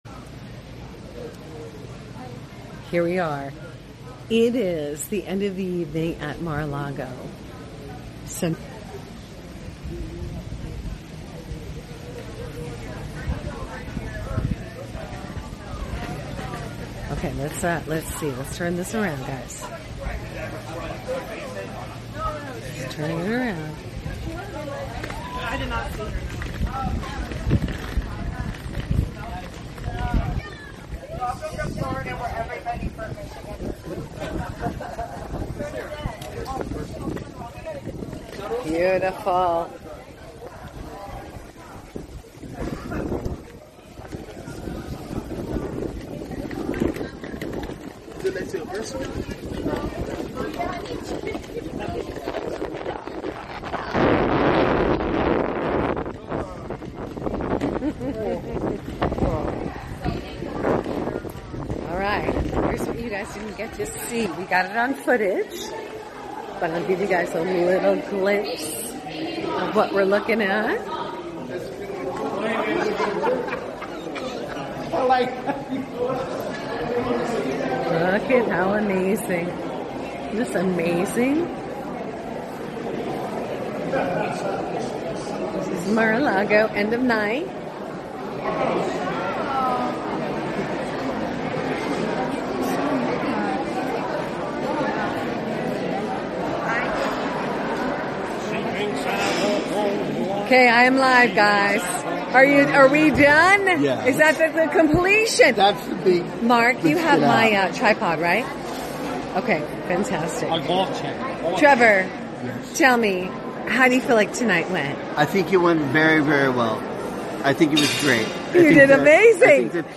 ➡ The text describes a lively evening at Mar-a-Lago in Florida, where people are seen enjoying and sharing their experiences. The night ends with people leaving and saying their goodbyes, expressing their gratitude for the event.